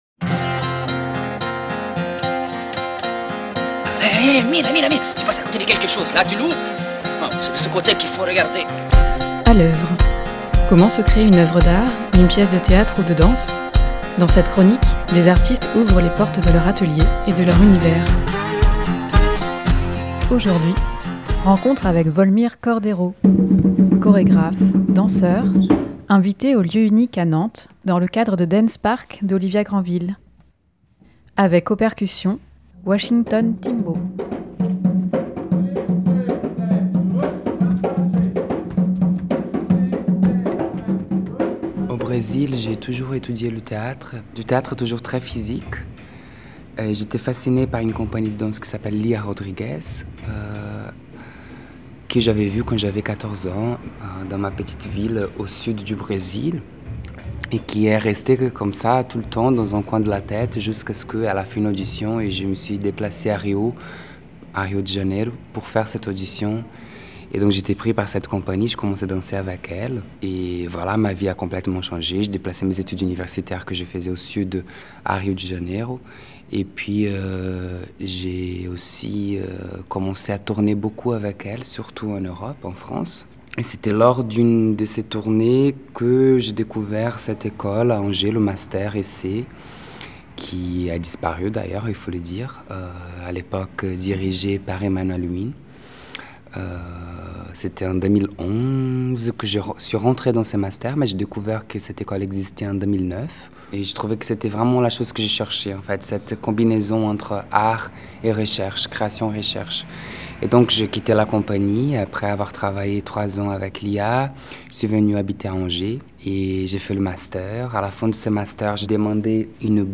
A l’œuvre : rencontre